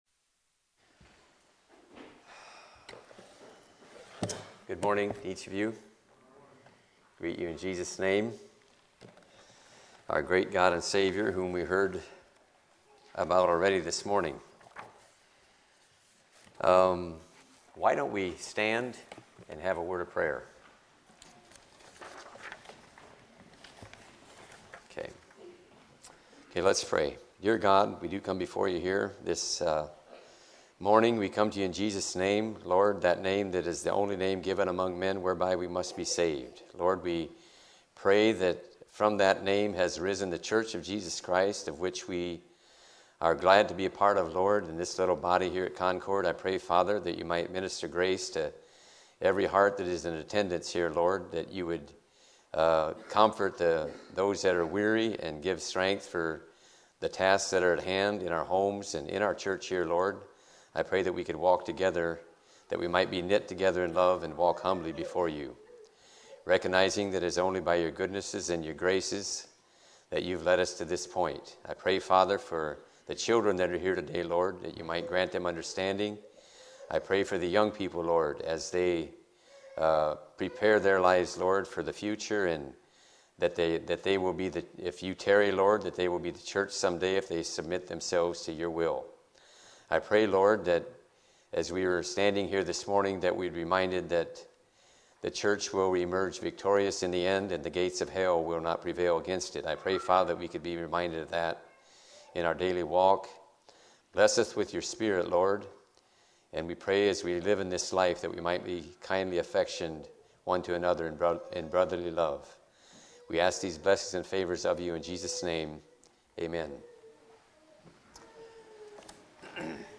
Sunday Morning Sermons